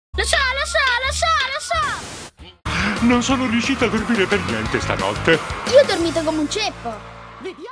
nel cartone animato "House of Mouse - Il Topoclub", in cui doppia Pinocchio.